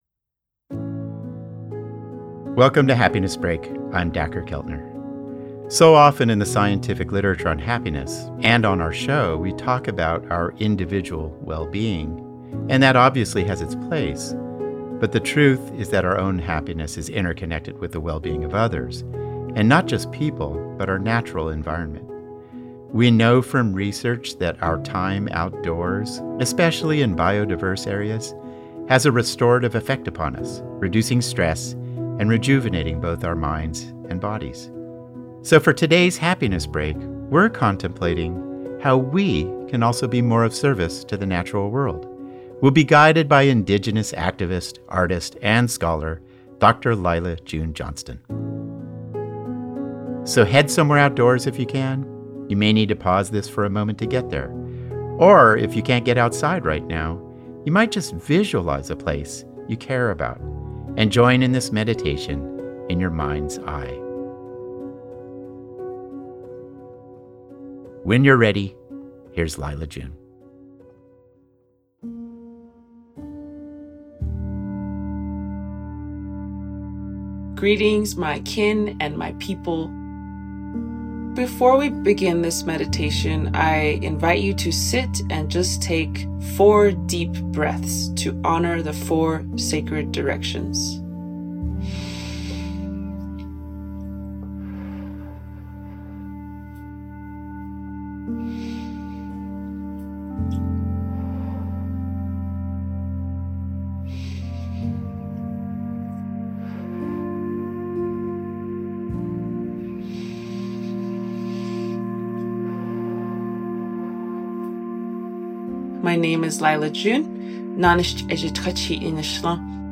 A Meditation on Giving Back to Earth